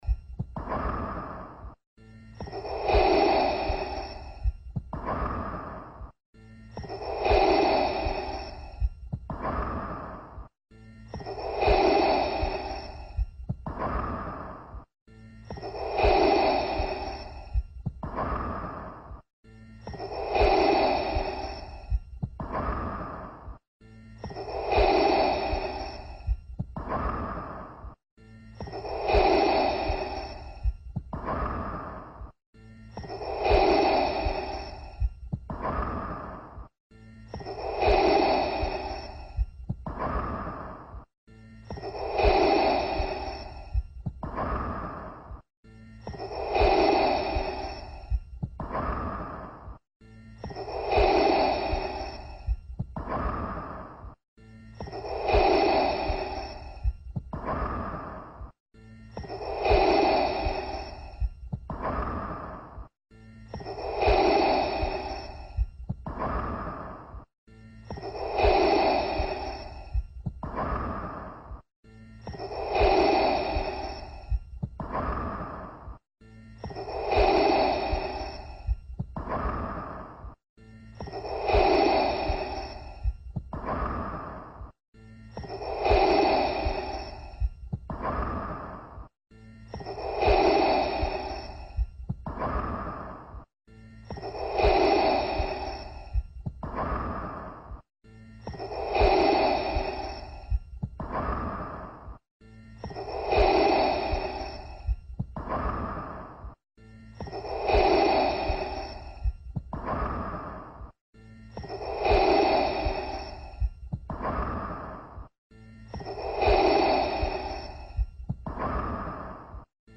Звуки Дарт Вейдера